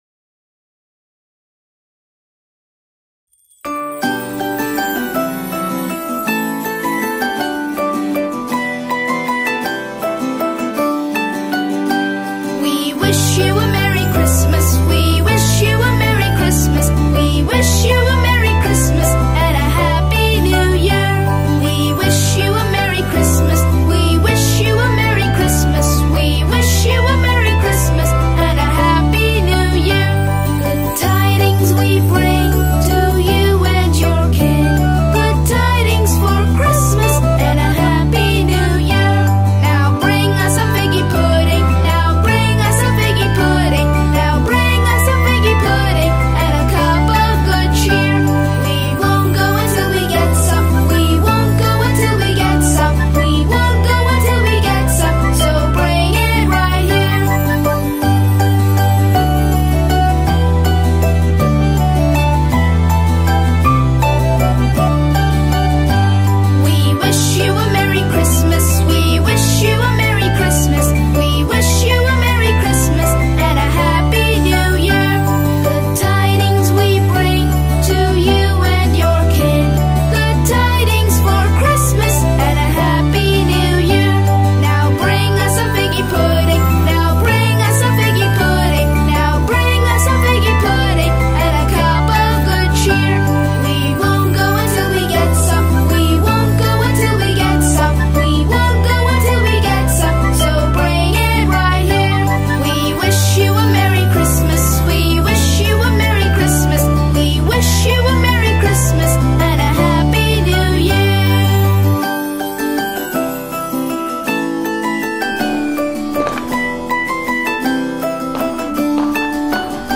Christmas Carols
Christmas Songs For Kids